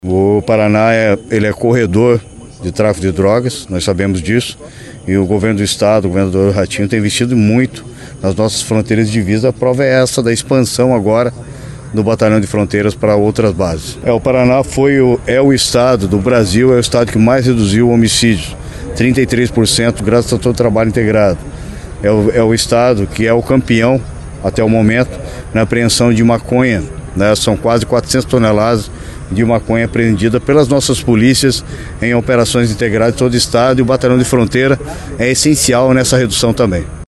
(Sonora secretário)